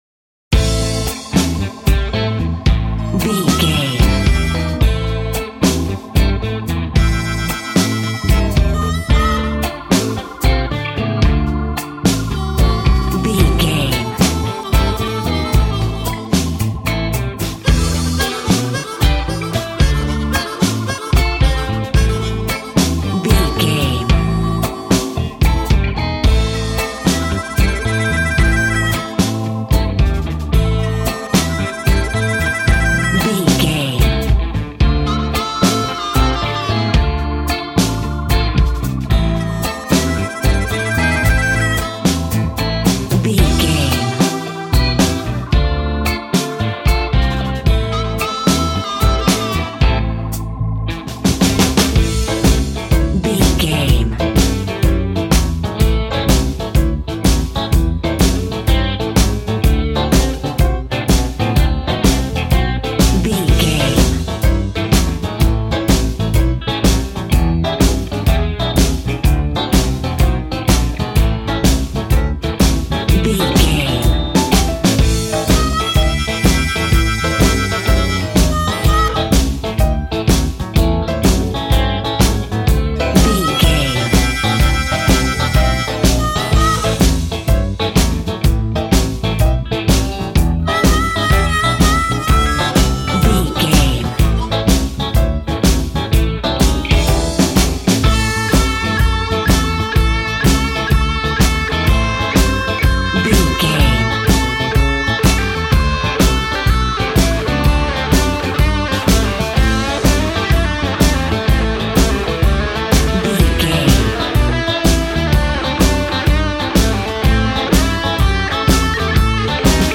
Aeolian/Minor
sad
mournful
bass guitar
electric guitar
electric organ
drums